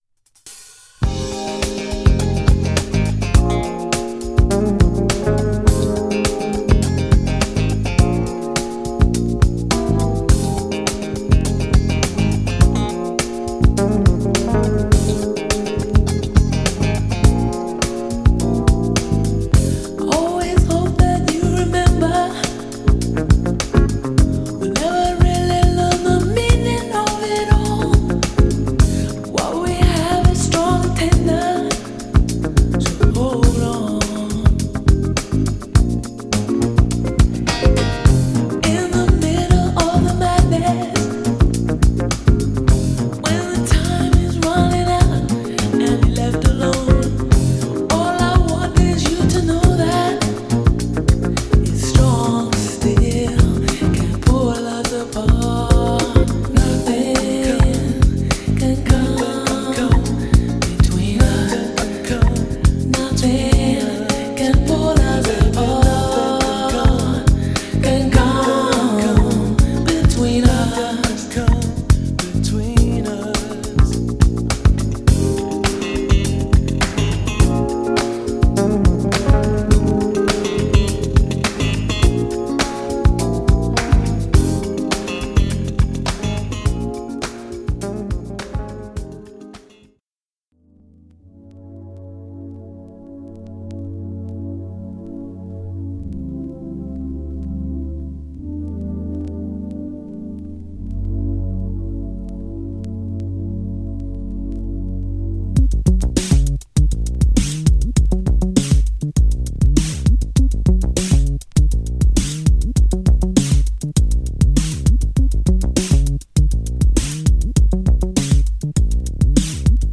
JAZZVOCAL